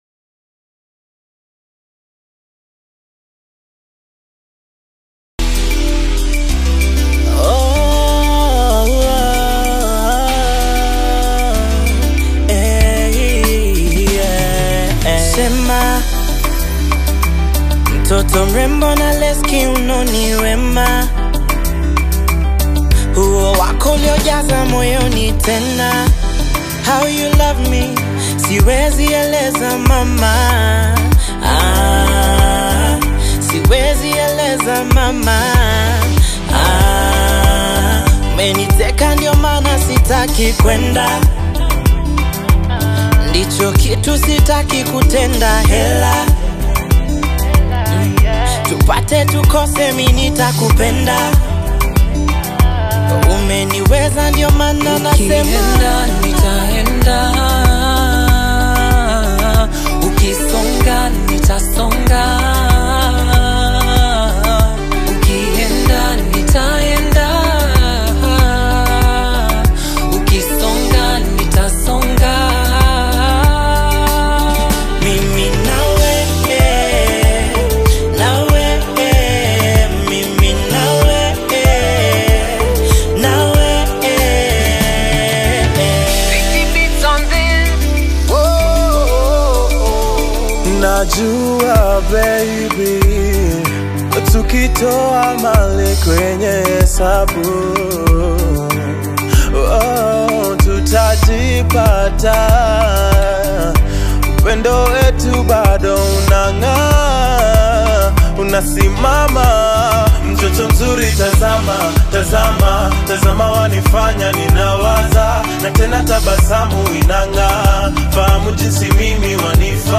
Boy Band